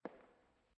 step_echo4.ogg